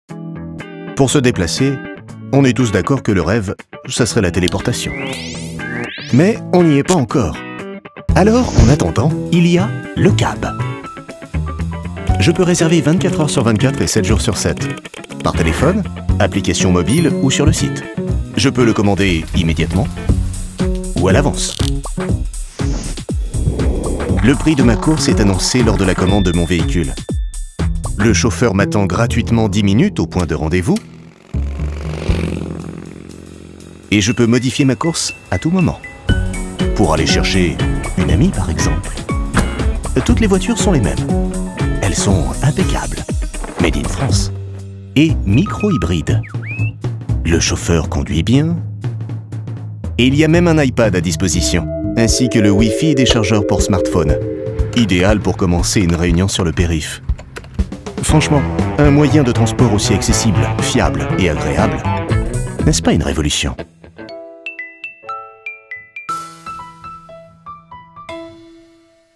Cool avec humour un peu british.
Film corporate résumant les services proposés par Le Cab.
Enregistré chez Monsieur du son.
Pour Le Cab, j’ai opté pour un ton médium grave, un ton qui se situe juste au milieu, ni trop grave, ni trop aigu.
Pour Le Cab, j’ai choisi un ton humoristique, décalé et sympathique, proche des gens, informatif, droit et cool.
Pour Le Cab, j’ai choisi un ton légèrement british, pour apporter une touche d’élégance et de sophistication, tout en restant accessible et sympathique.